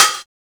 Lis Hat.wav